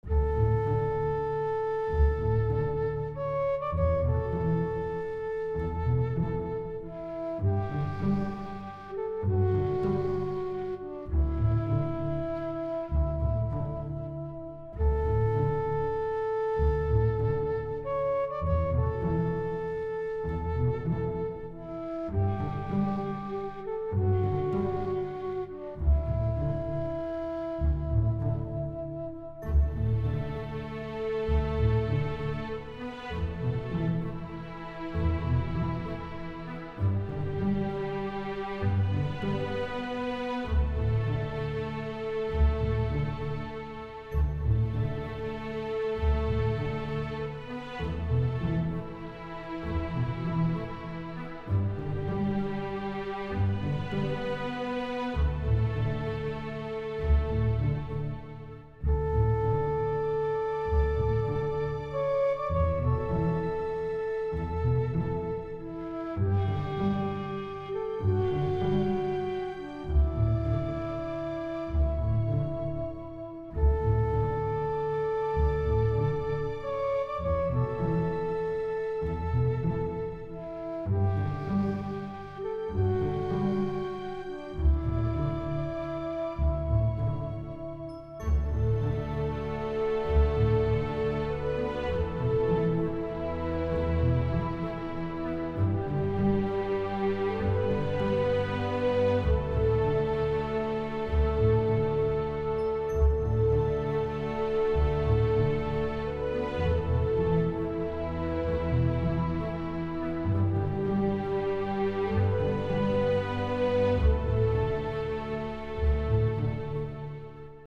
I like their orchestra that is soo relaxing.